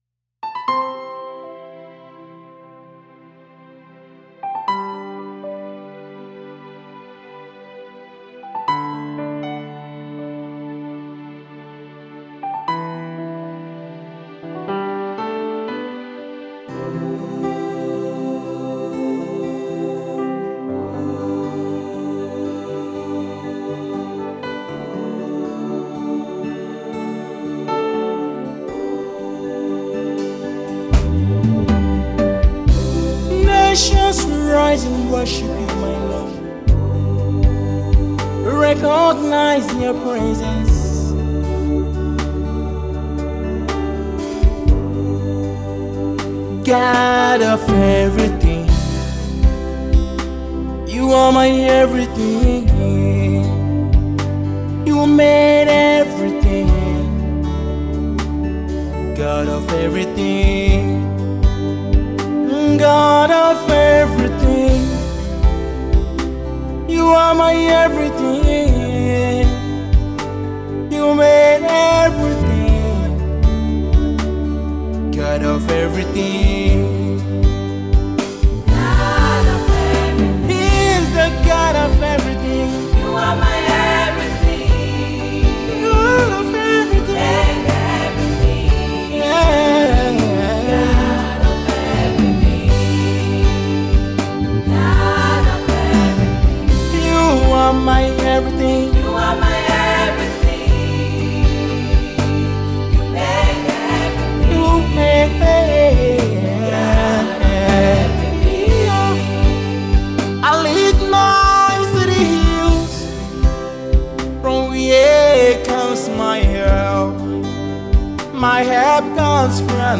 Afro Contemporary Gospel
Heart Worship